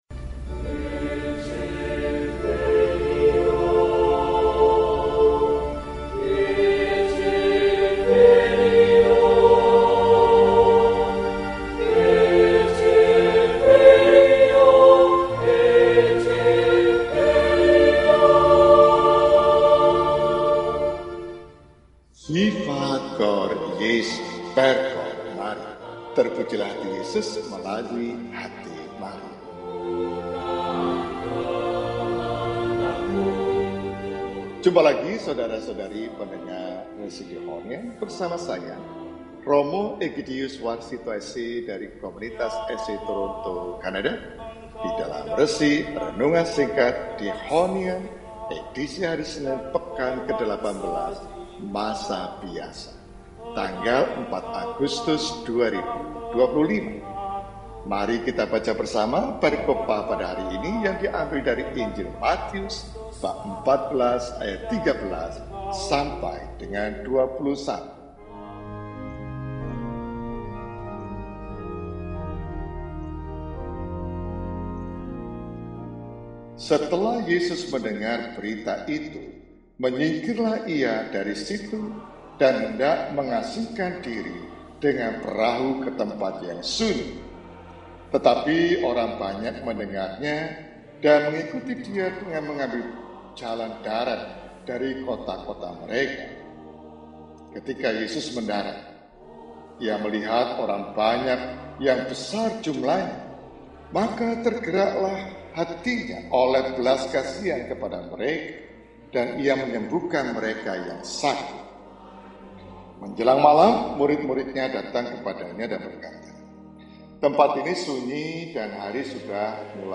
Senin, 04 Agustus 2025 – Peringatan Wajib Santo Yohanes Maria Vianney (imam, 1786-1859) – RESI (Renungan Singkat) DEHONIAN